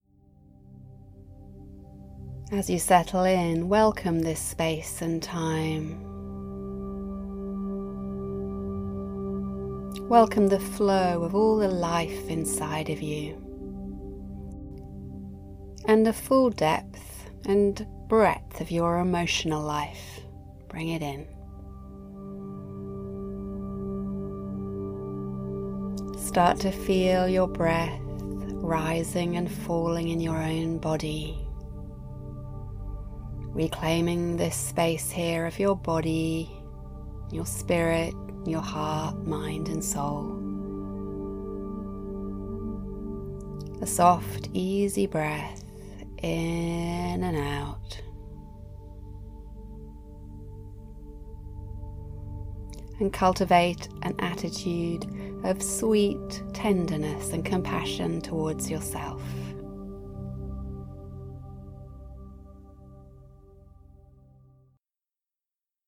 The album contains six guided meditations, each from 16 to 24 minutes long, plus a brief recording on how to use the meditations.